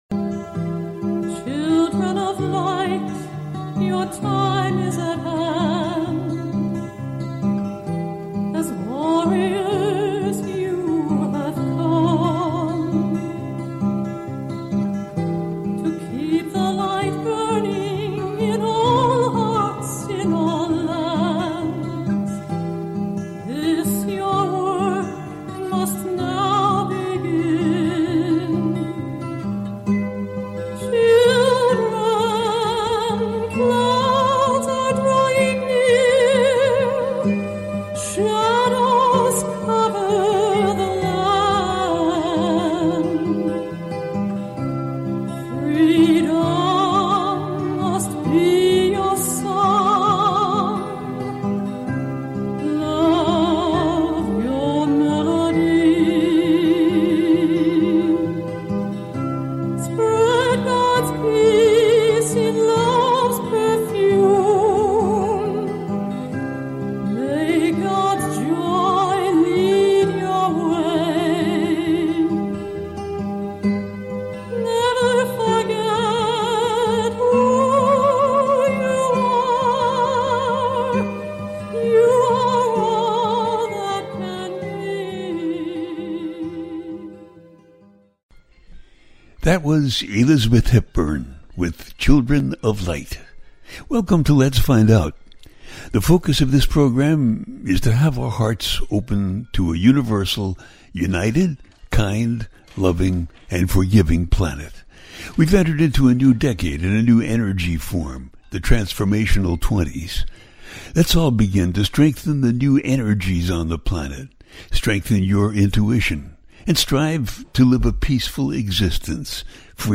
Guided Meditation – Give Yourself Love-Then Pass It On
The listener can call in to ask a question on the air.
Each show ends with a guided meditation.